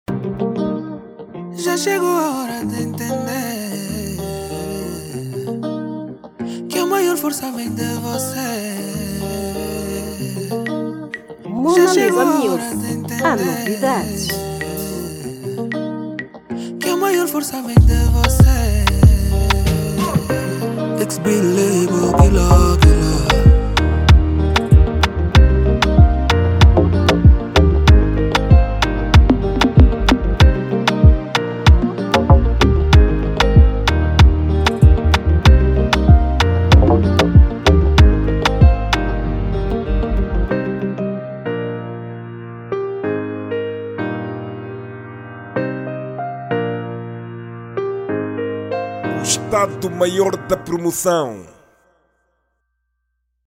Gênero : Zouk